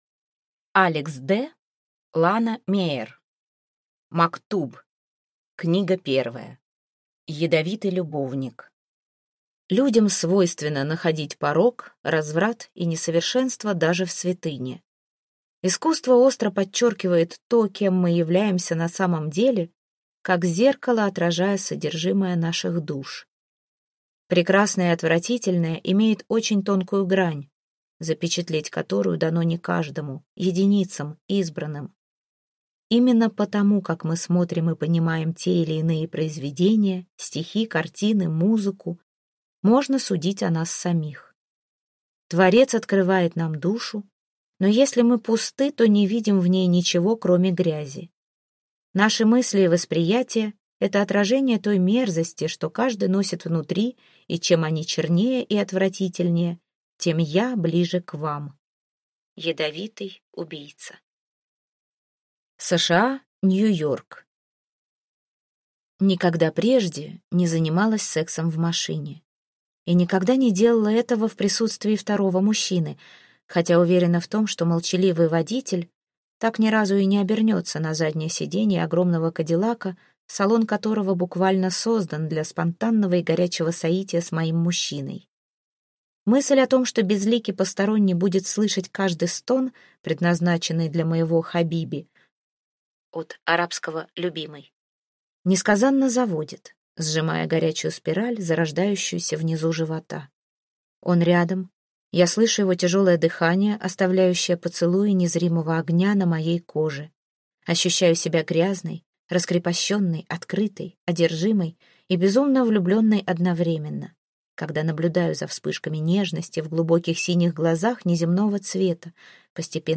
Аудиокнига Мактуб. Книга 1. Ядовитый любовник | Библиотека аудиокниг